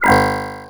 fail.wav